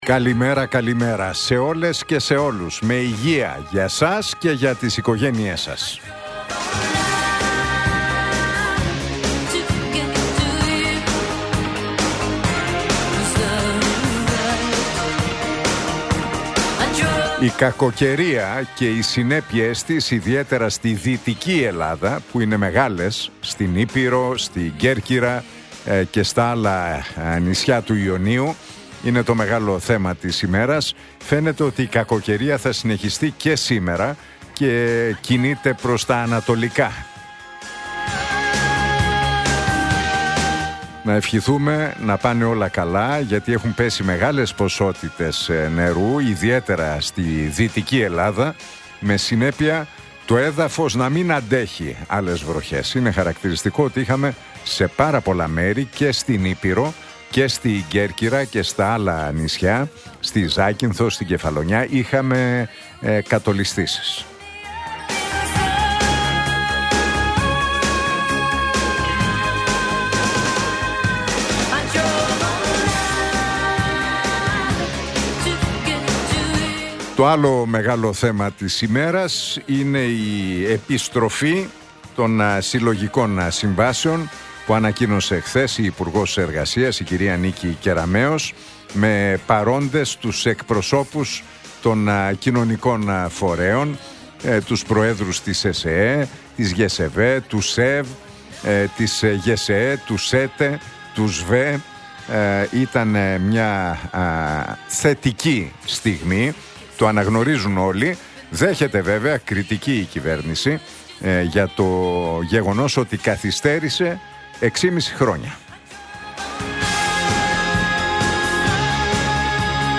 Ακούστε το σχόλιο του Νίκου Χατζηνικολάου στον ραδιοφωνικό σταθμό Realfm 97,8, την Πέμπτη 27 Νοεμβρίου 2025.